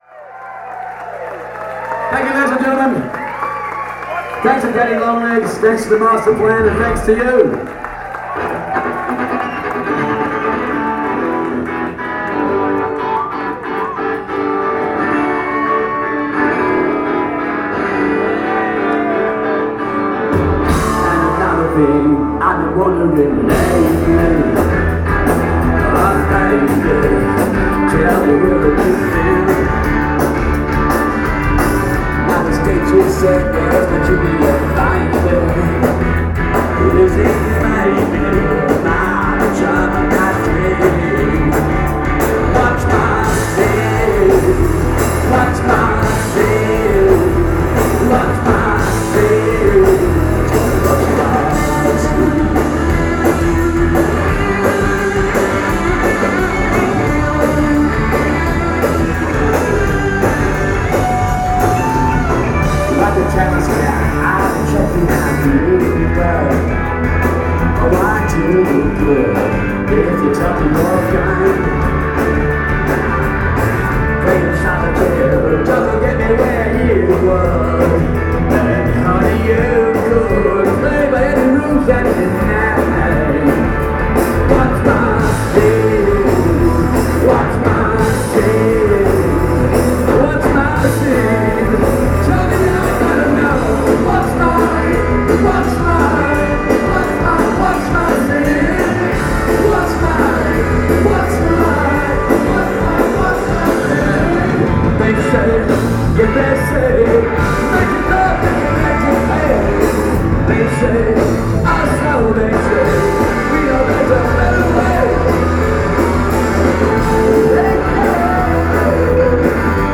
a good-time rock pop band from Australia
great 90’s era college rock vocals
Fun show.